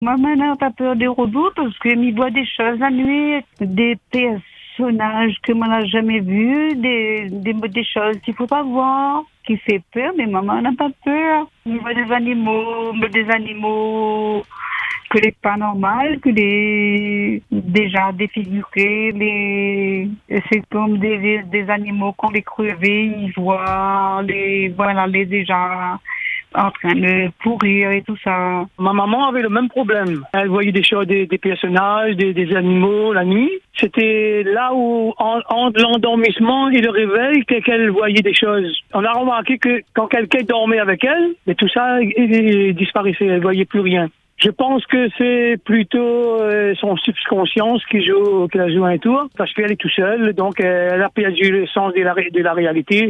Des auditeurs nous ont livré des témoignages troublants, vécus parfois en pleine campagne, parfois même chez eux, dans le calme le plus total.